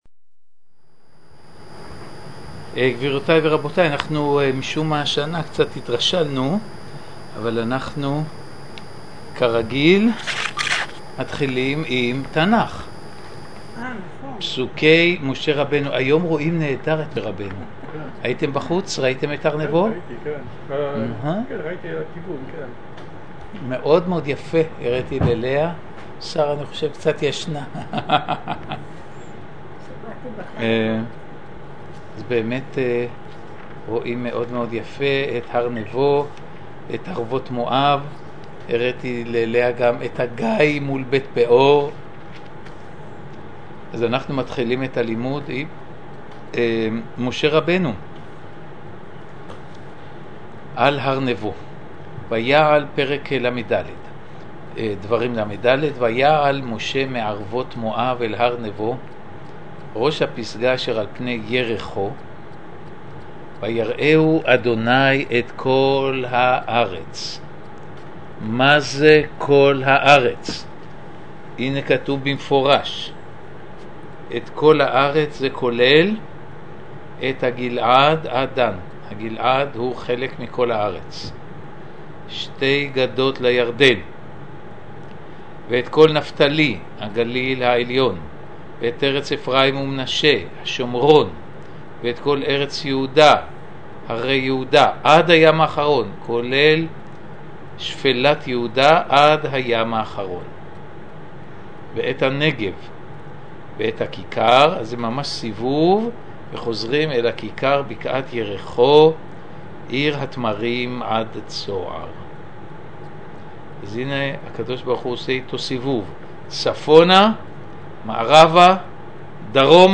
מבוא שיעורים במסגרת יום העיון החודשי באוירה ארץ ישראלית בבית חגלה על פני יריחו...